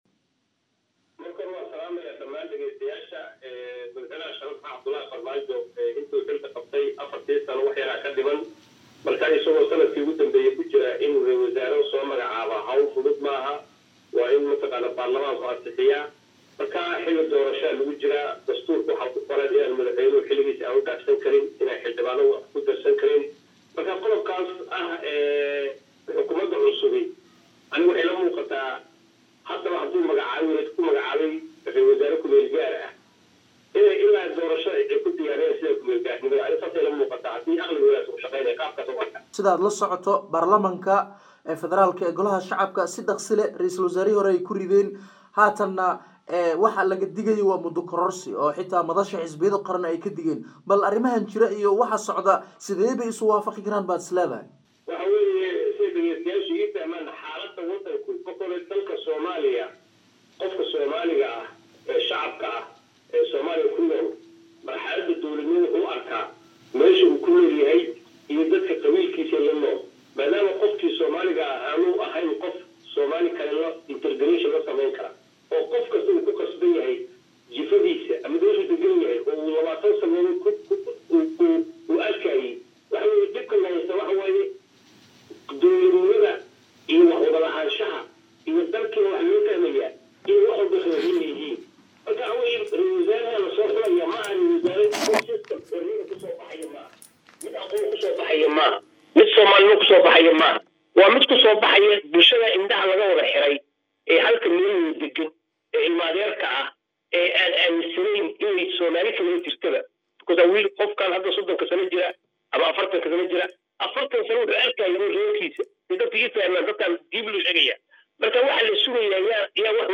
Danjire Maxamed Maxamuud Caalim oo ka Mid ah Aqoon yahanada Soomaaliyeed ee ku nool Dalka Finland ayaa waraysi uu bixiyay Kaga hadlay Isbadalka siyaasadeed ee ee Dalka Soomaaliya ka Jira